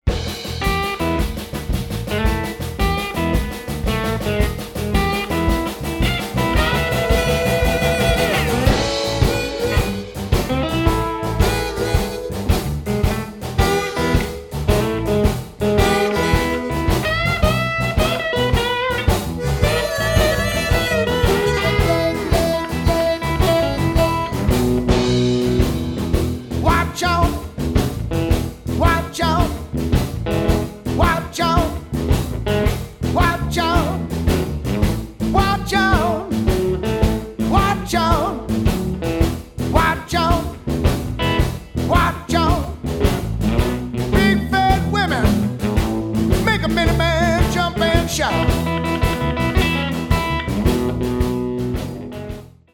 harmonica
Blues